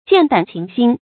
劍膽琴心 注音： ㄐㄧㄢˋ ㄉㄢˇ ㄑㄧㄣˊ ㄒㄧㄣ 讀音讀法： 意思解釋： 比喻既有情致，又有膽識（舊小說多用來形容能文能武的才子）。